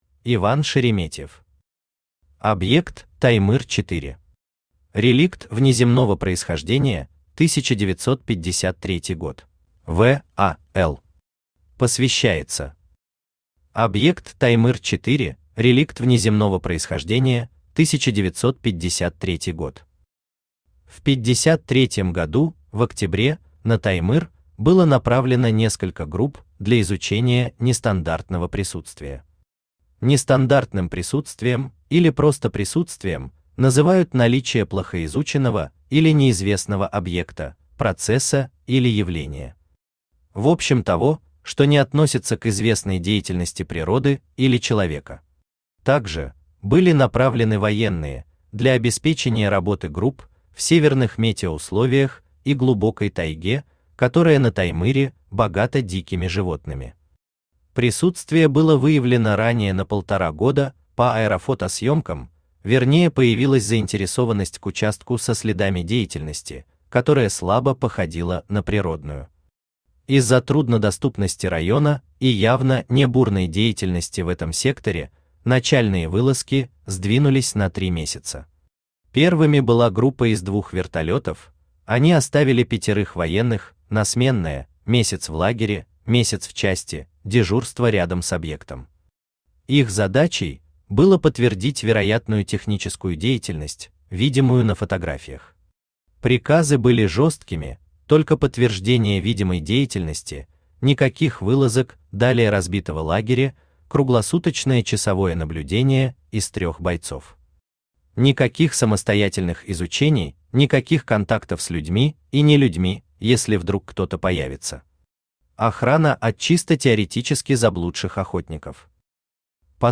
Реликт внеземного происхождения, 1953 год Автор Иван Шереметьев Читает аудиокнигу Авточтец ЛитРес.